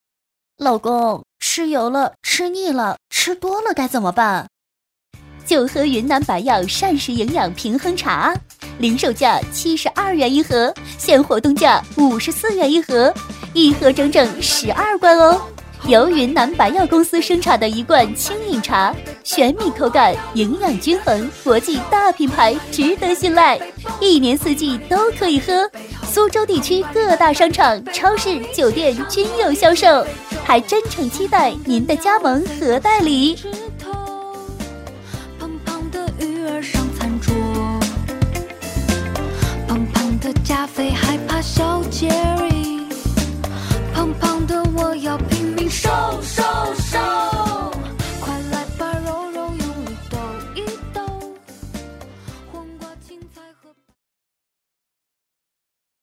女52实惠甜美-纵声配音网
女52 老公，吃油了.mp3